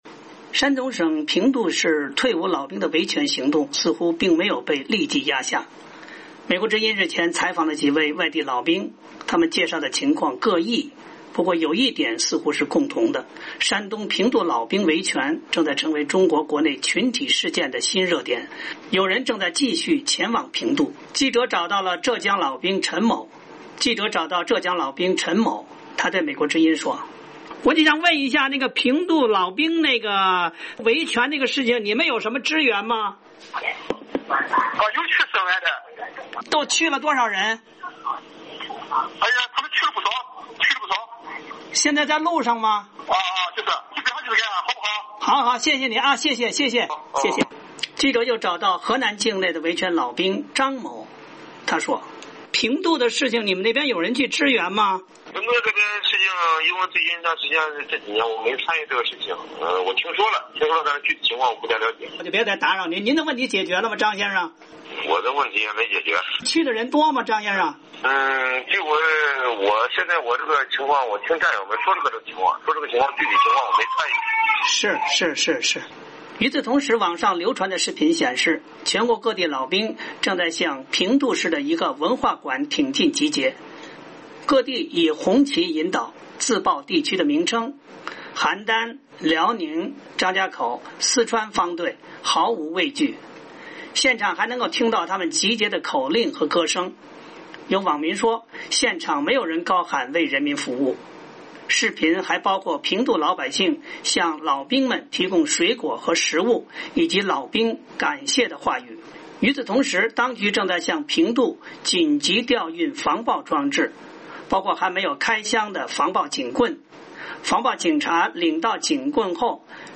美国之音日前采访了几位外地老兵，他们介绍了有关情况，略显紧张。